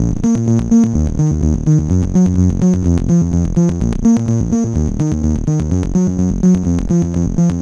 Crackly Arp Bb 126.wav